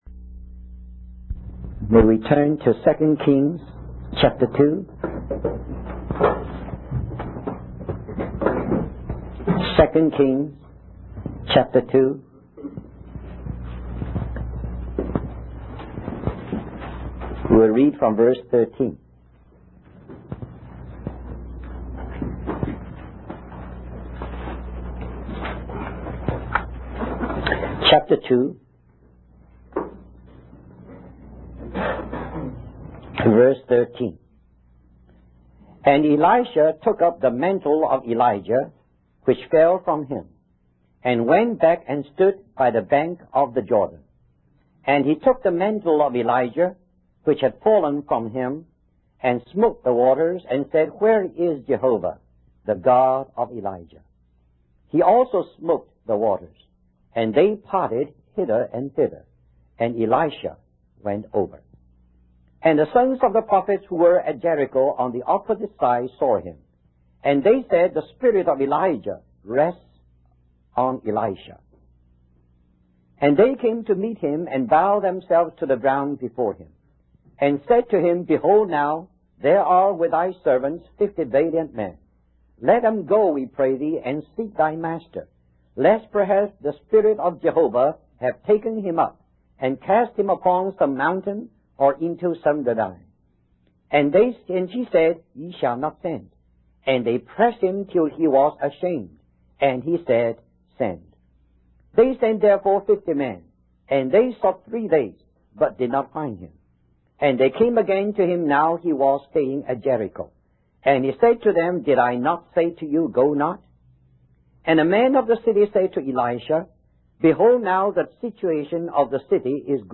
In this sermon, the preacher focuses on the ministry of Elisha and the importance of imparting the life of Christ in our own ministries.